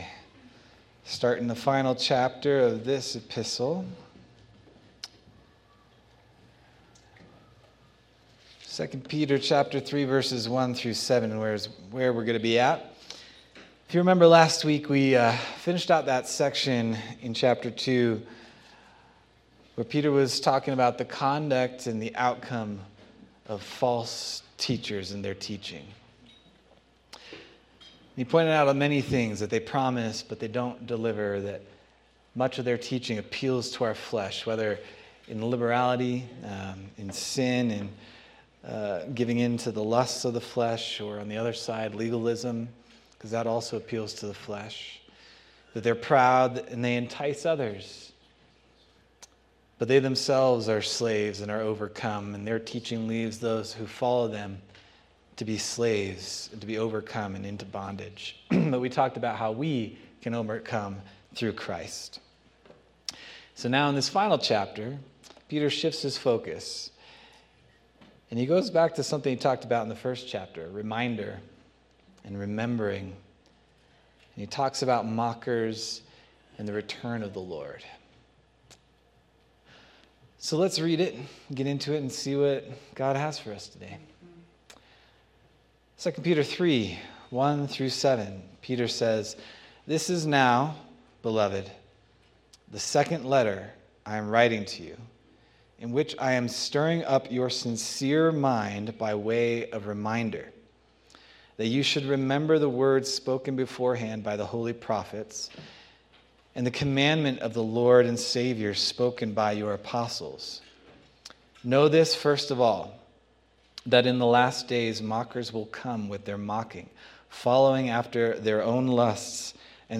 November 23rd, 2025 Sermon